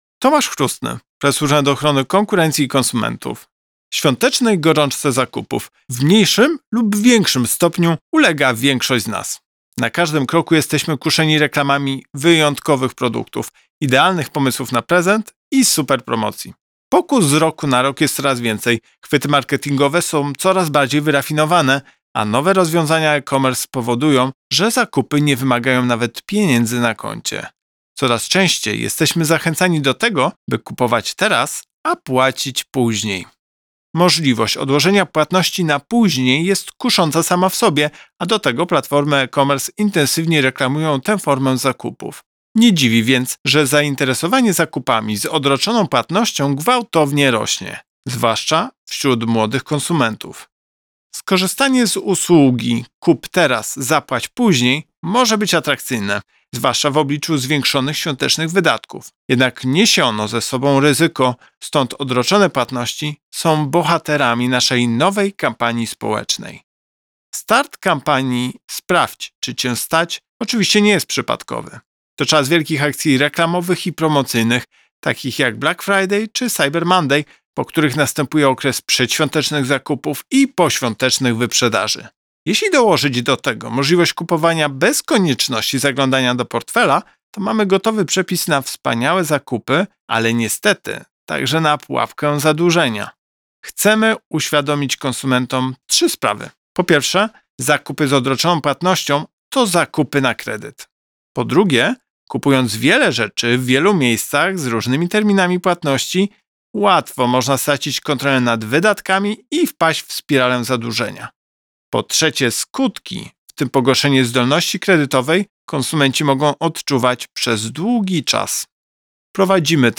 Plik mp3 z wypowiedzią prezesa UOKiK do pobrania